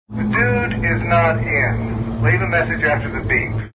Beep.  Dial tone.